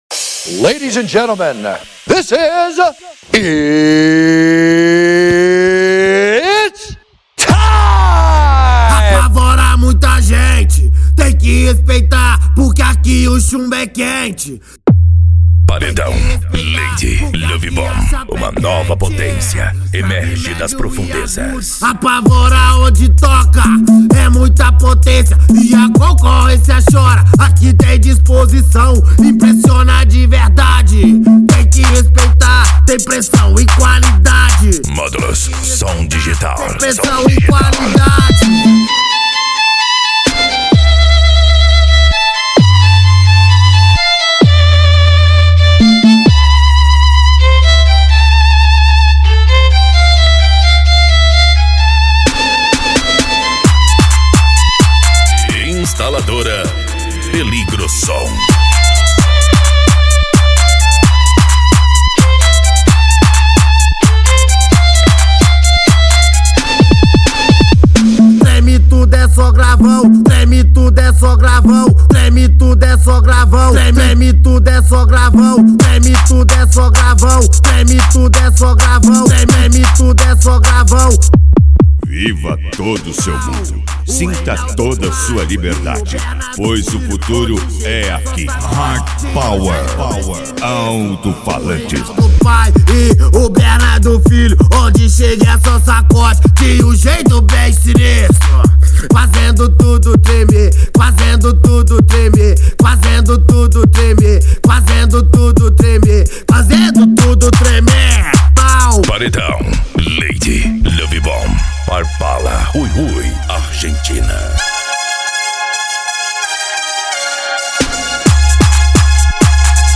Bass
Deep House
Eletronica
Psy Trance